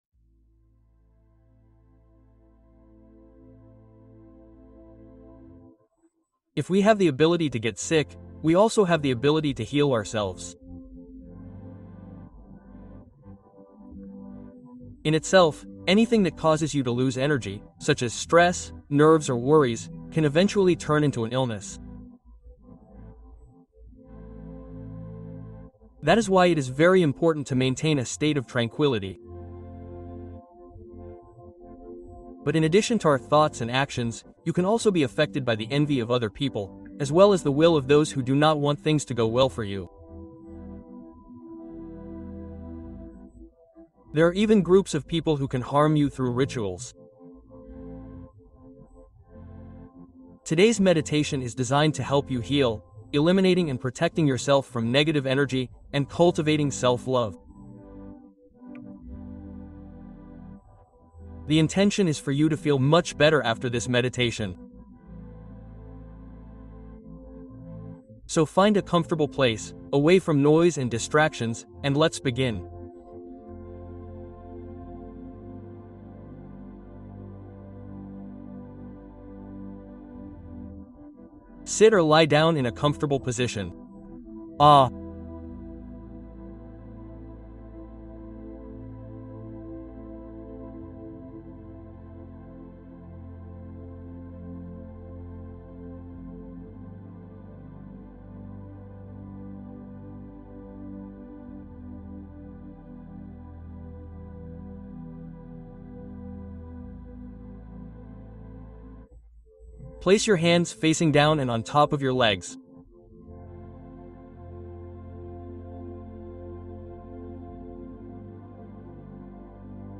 Limpieza Energética Consciente: Meditación para Aligerar la Carga Emocional